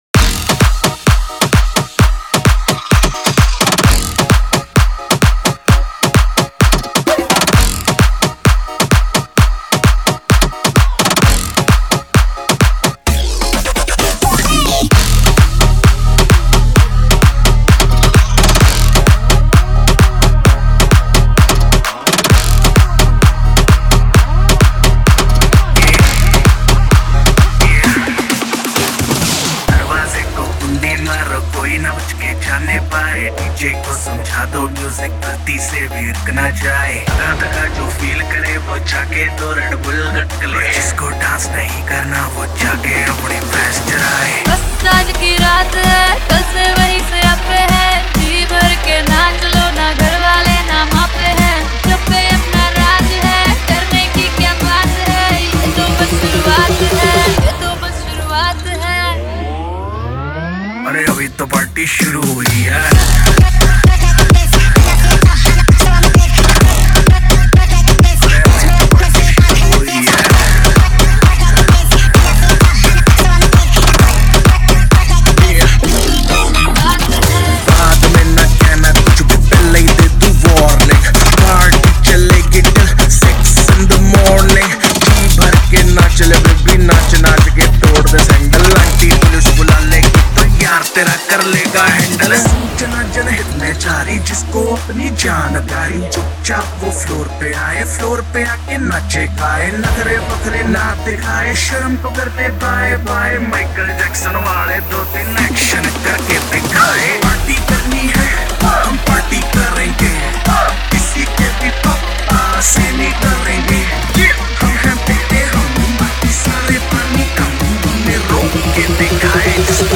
EDM Remix | Dance Remix Song
Category: Latest Dj Remix Song